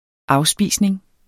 Udtale [ ˈɑwˌsbiˀsneŋ ]